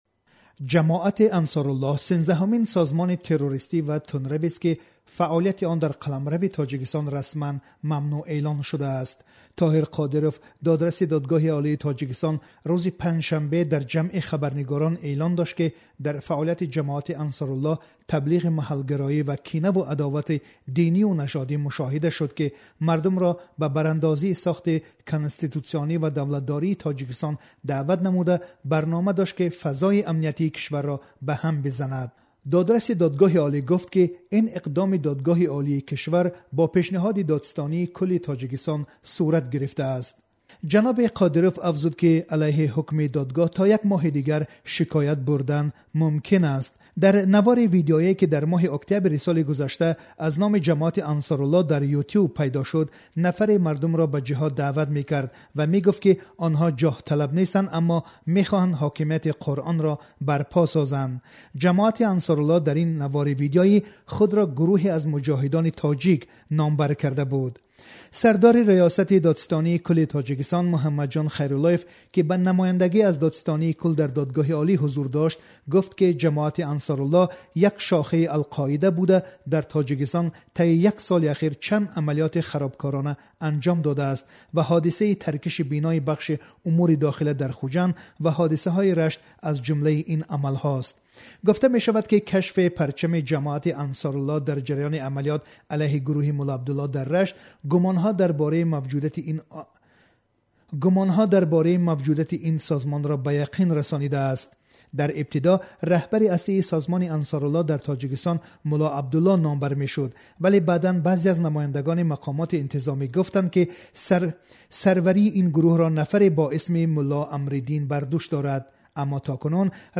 гузориши